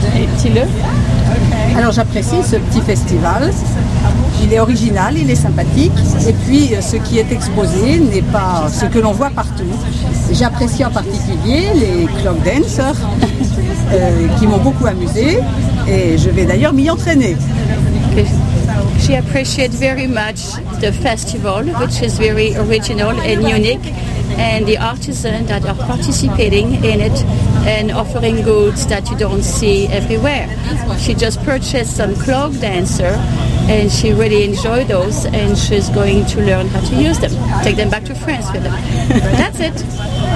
In July 2001 Worthington Libraries invited the community to share their earliest memories and fondest recollections of life in Worthington at the Worthington FolkFEST.